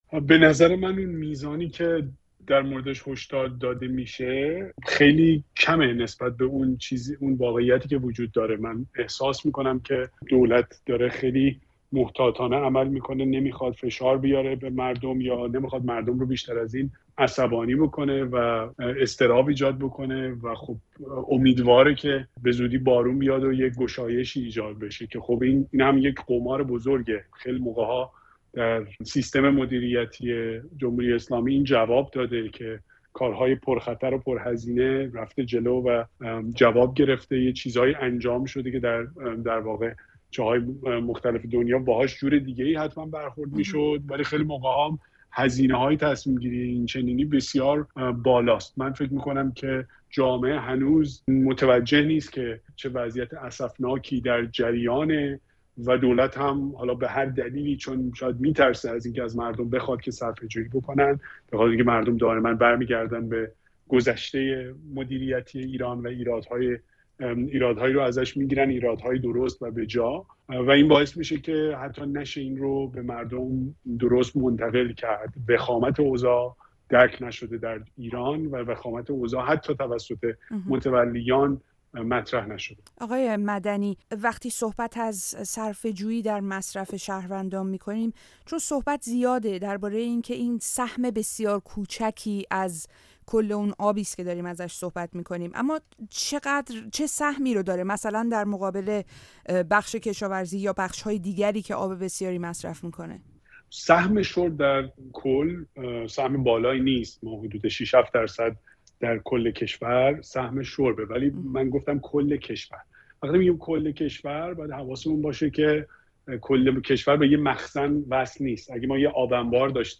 گفت‌وگو با کاوه مدنی؛ بحران کم‌آبی چقدر جدی است و از دست مردم چه برمی‌آید؟